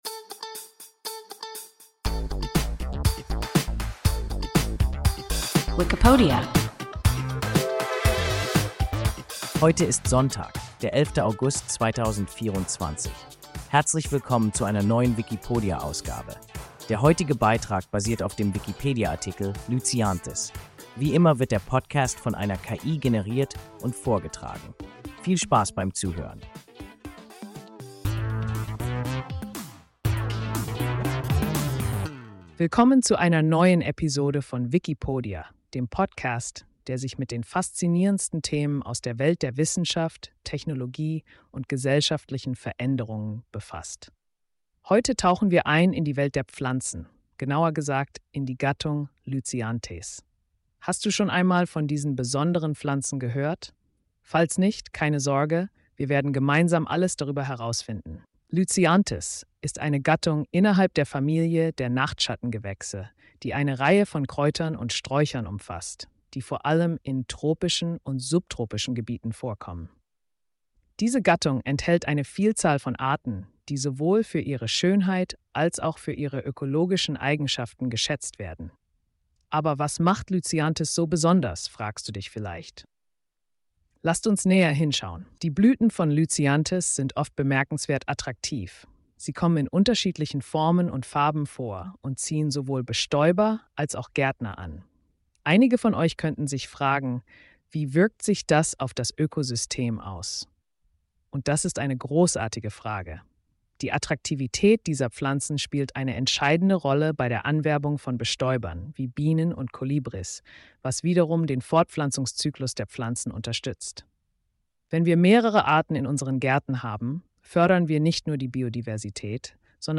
Lycianthes – WIKIPODIA – ein KI Podcast